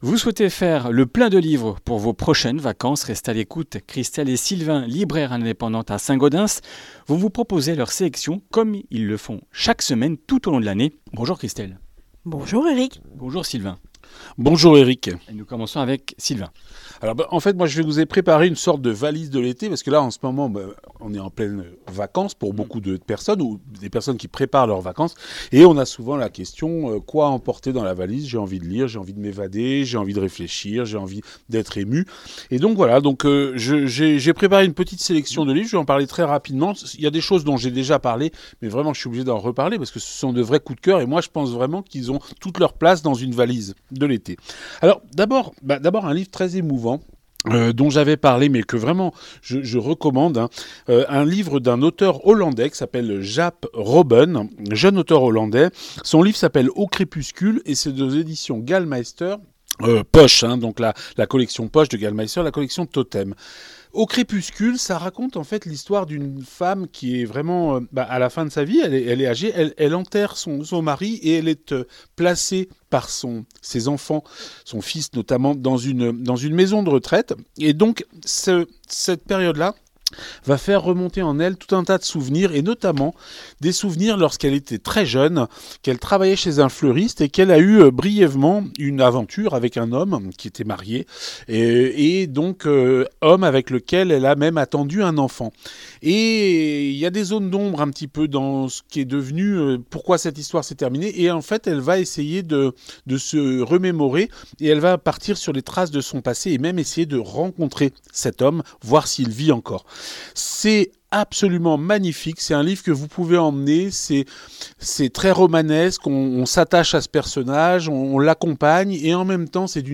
Comminges Interviews du 25 juil.